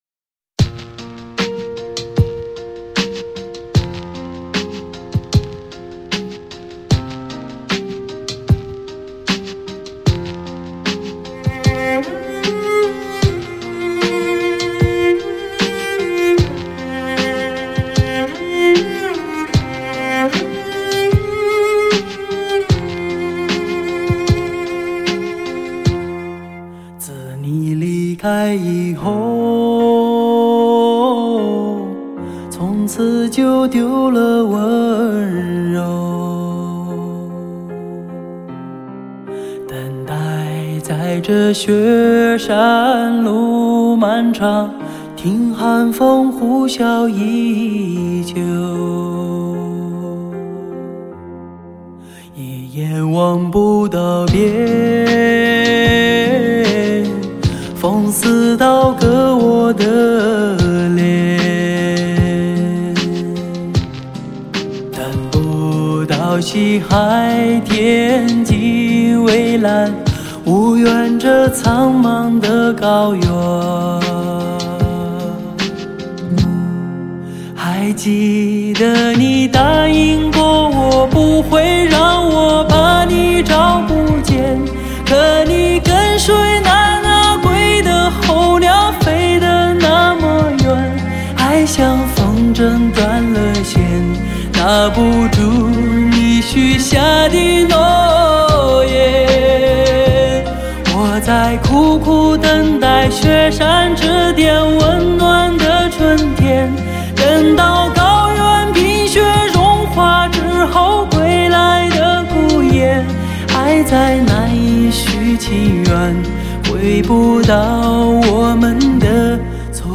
天籁女声
顶级天籁女声发烧碟，必唯天作之合HI-FI极致女声。